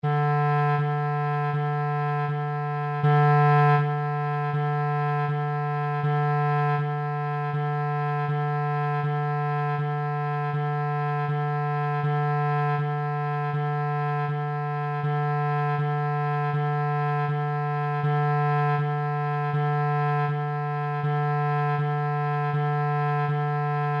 Velaquí atoparedes os arquivos de audio coas notas da escala musical:
Nota RE